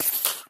Sound / Minecraft / mob / creeper / say4.ogg